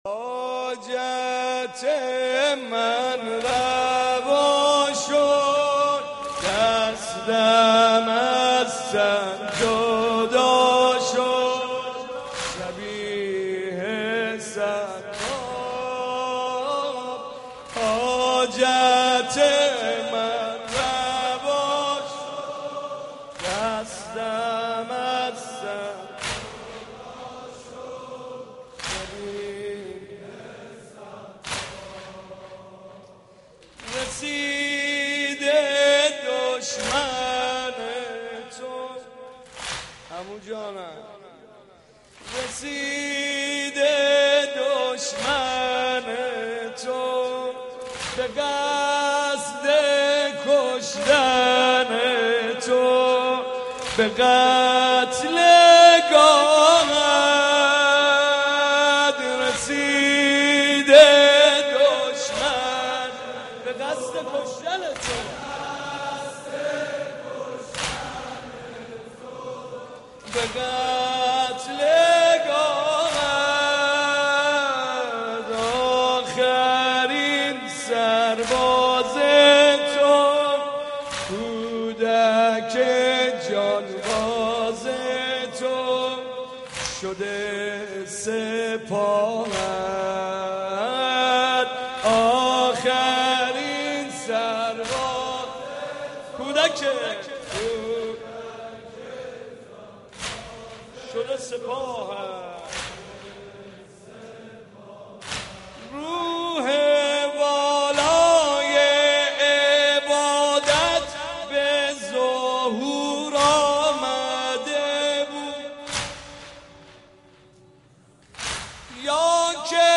محرم 88 - سینه زنی 3